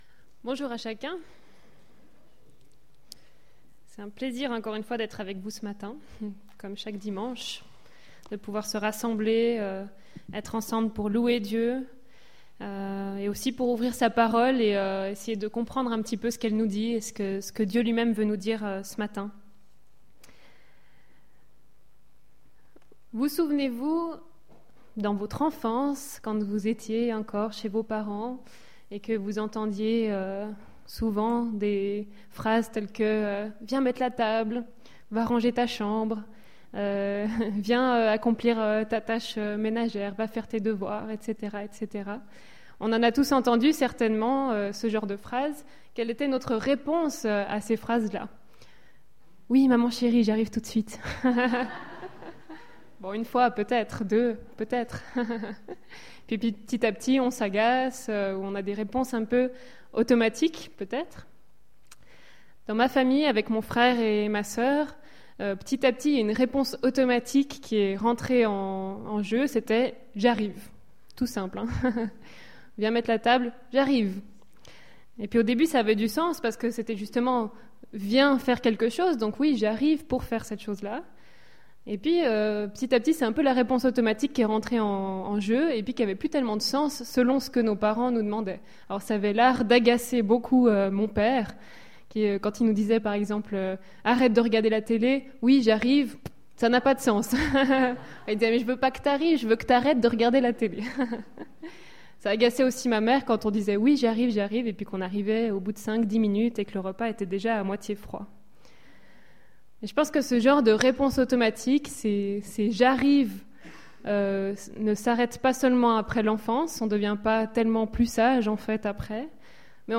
Culte du 5 juin 2016